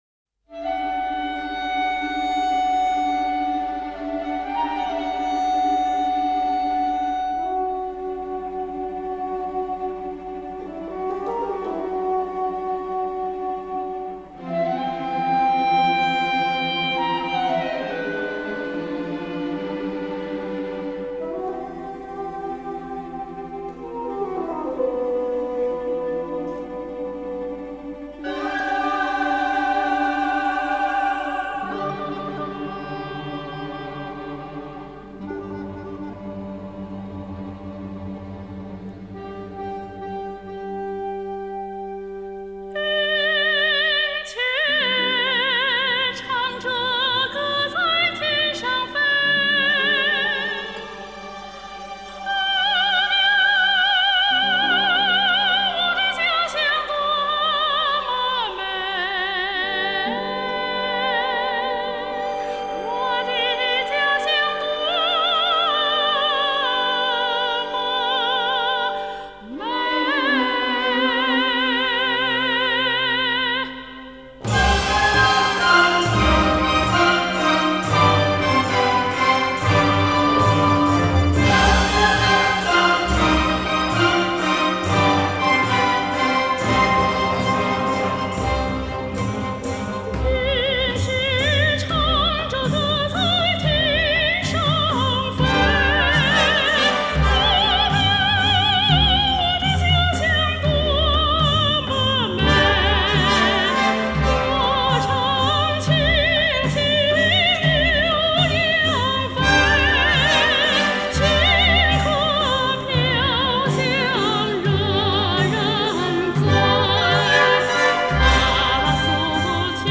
声乐套曲